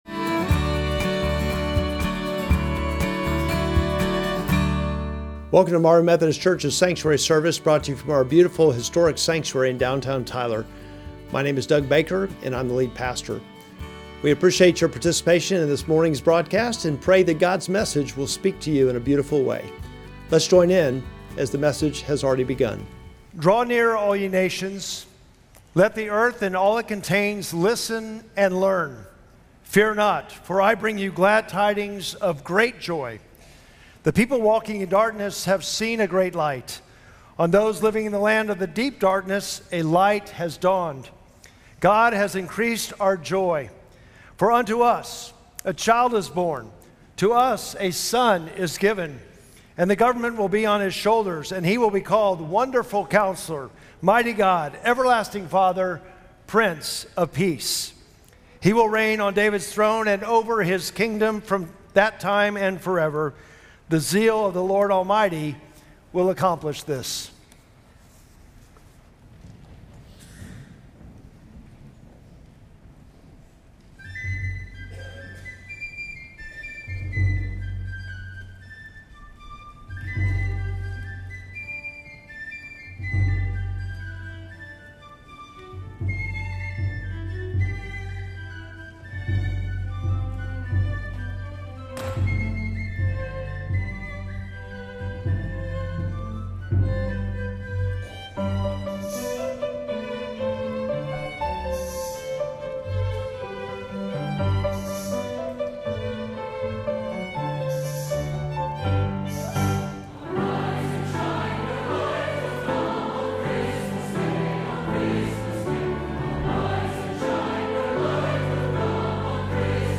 Lessons and Carols | Tidings of Great Joy | Marvin Methodist Church
Traditional Worship